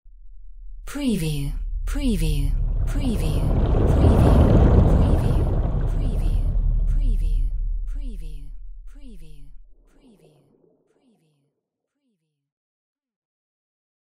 Submarine water movement 01
Stereo sound effect - Wav.16 bit/44.1 KHz and Mp3 128 Kbps